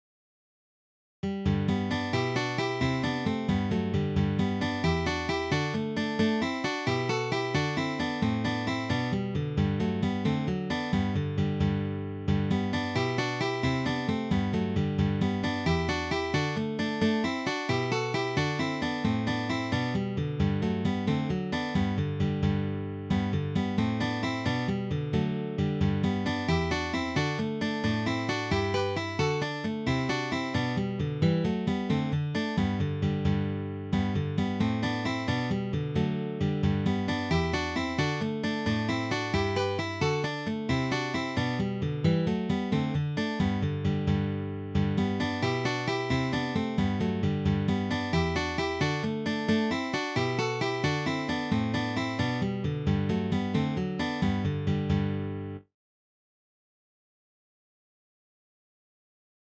arranged for three guitars
This Irish folk selection is arranged for guitar trio.